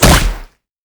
sfx_skill 09_2.wav